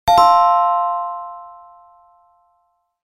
1. bells